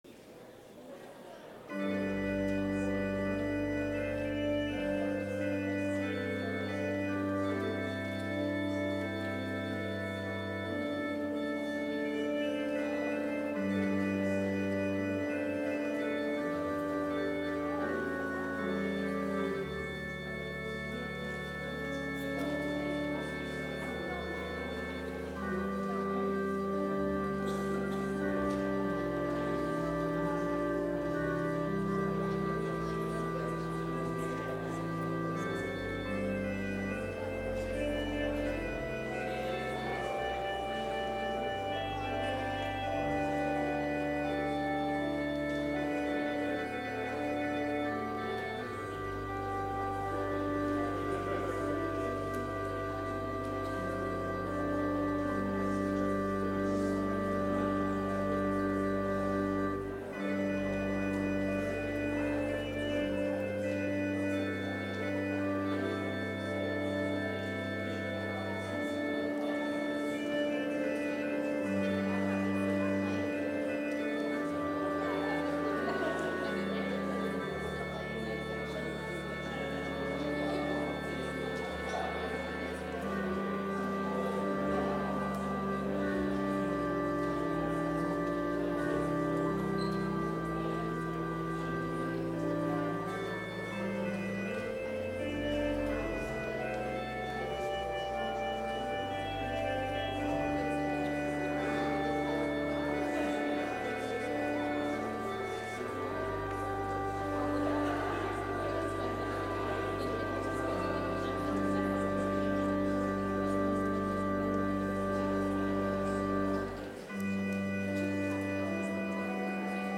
Complete service audio for Chapel - Friday, November 17, 2023